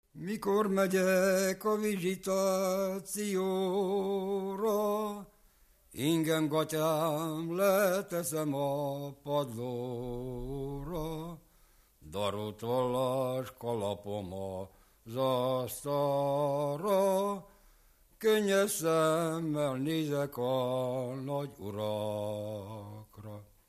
Erdély - Kolozs vm. - Váralmás
ének
Stílus: 6. Duda-kanász mulattató stílus
Kadencia: 8 (5) 7 1